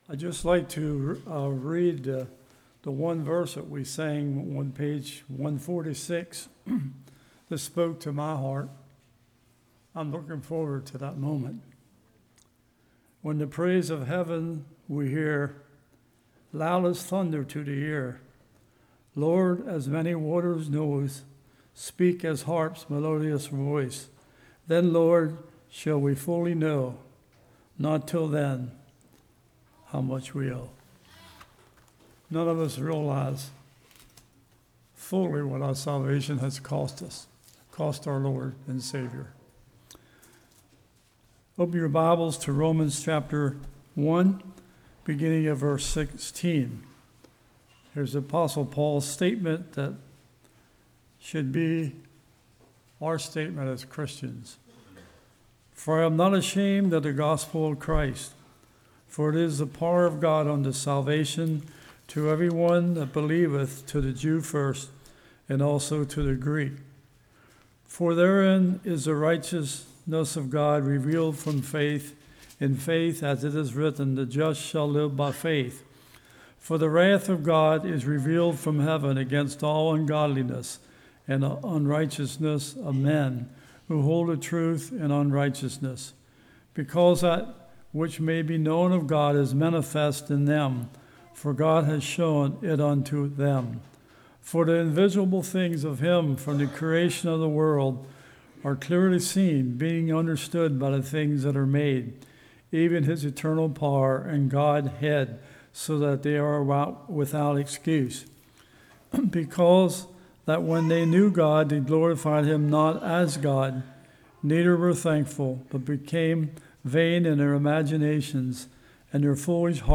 Series: Fall Revival 2015